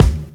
lbrek_kick.wav